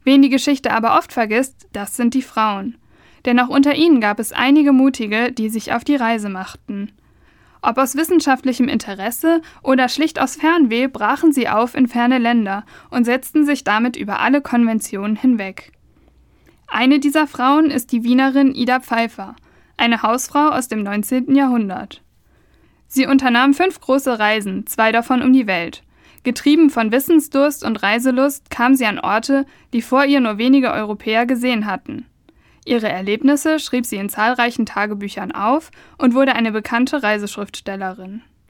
Radio Micro-Europa, der Tübinger Campusfunk: Sendung (348) „Mit Korsett und Pistole – Ida Pfeiffers Reise um die Welt“ (Feature) am Sonntag, den 28. Februar 2021 von 12 bis 13 Uhr im Freien Radio Wüste Welle 96,6 – Kabel: 97,45 Mhz, auch als Live-Stream im Internet.